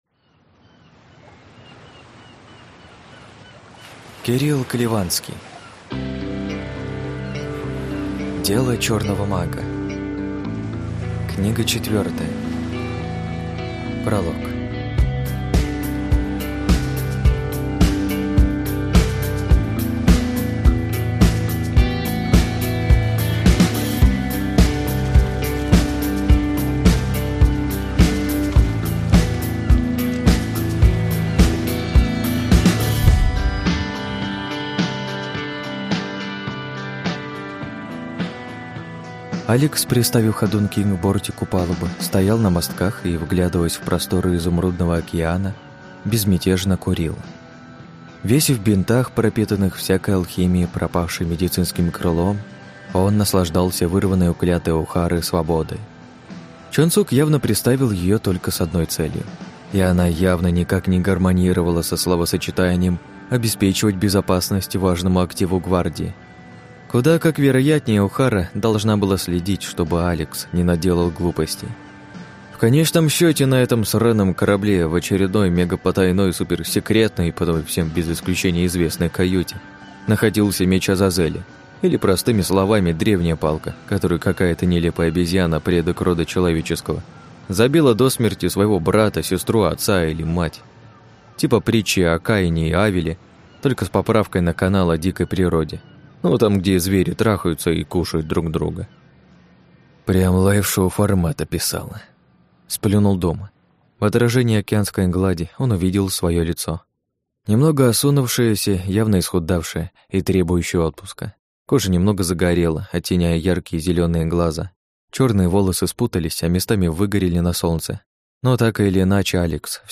Аудиокнига Дело черного мага. Книга 4 | Библиотека аудиокниг